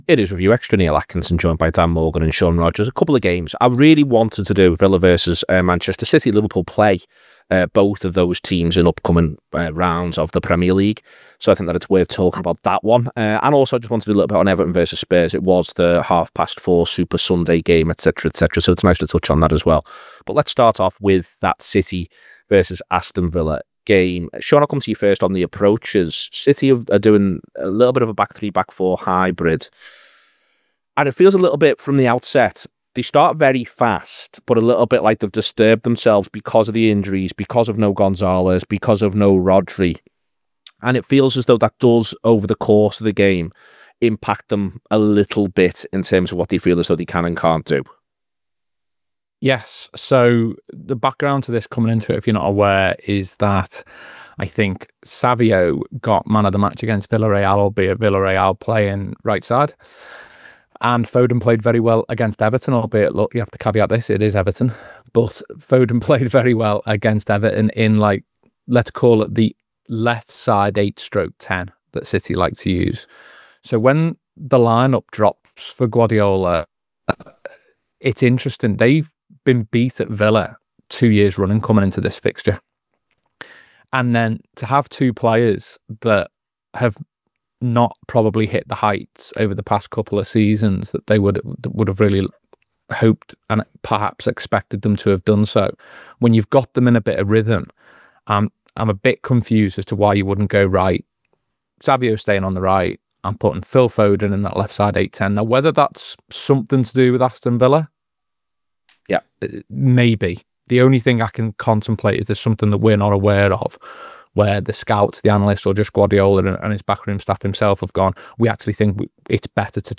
Below is a clip from the show – subscribe for more review chat…